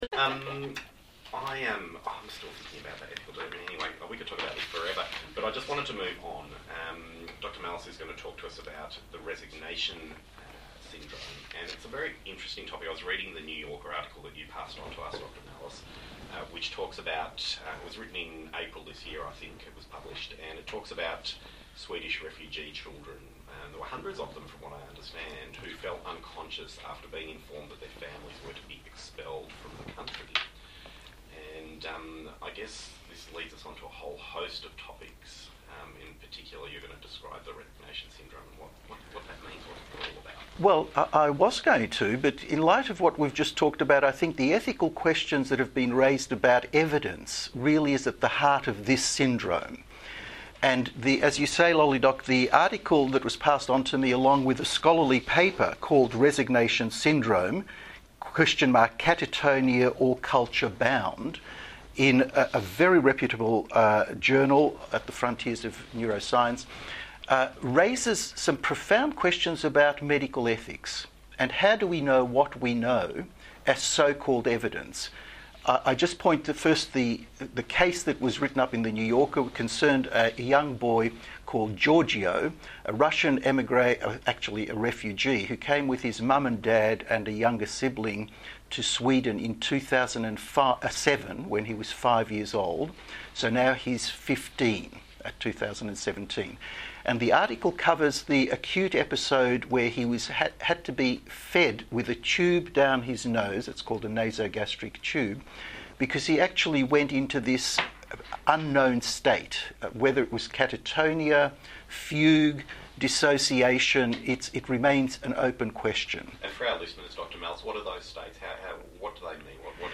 Discussion on the Radiotherapy program on RRR 102.7 FM, Sunday 3rd September, 2017.